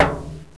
Son subtil
Son de l'objet heurté.
TINHIT12.WAV